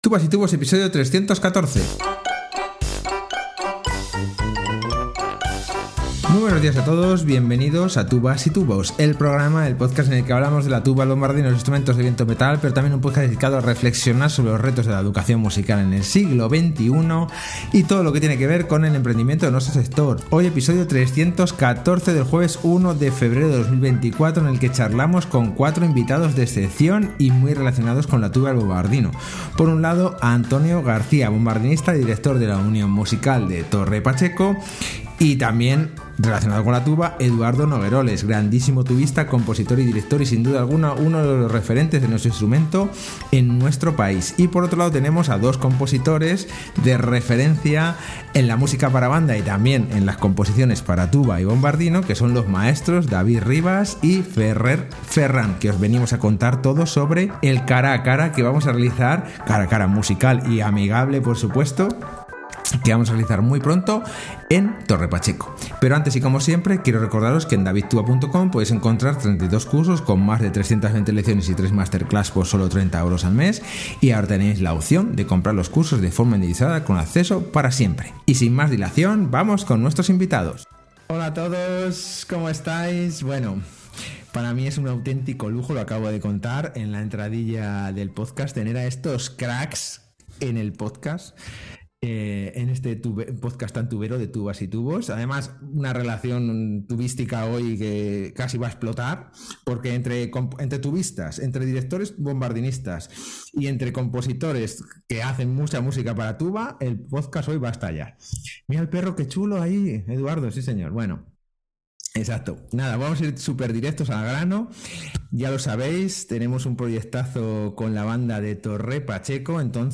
Entrevista a cuatro bandas con todos los protagonistas del Cara a Cara Musical en Torre Pacheco.